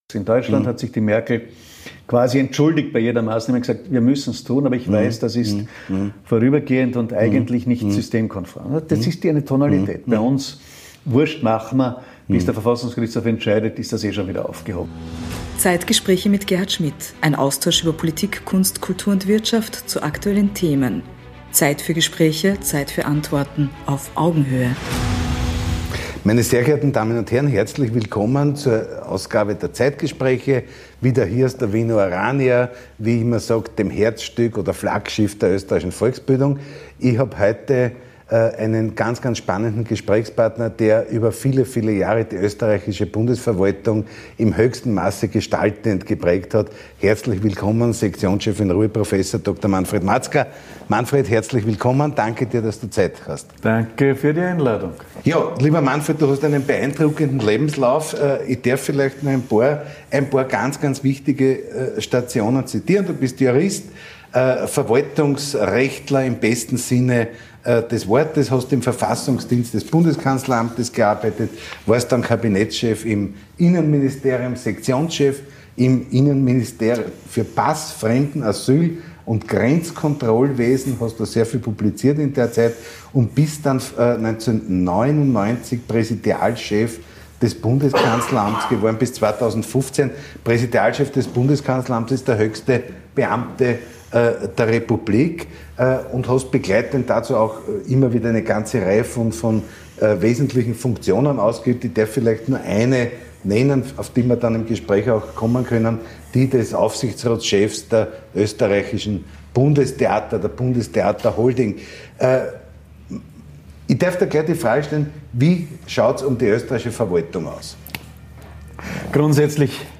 Zeit für Gespräche – Zeit für Antworten.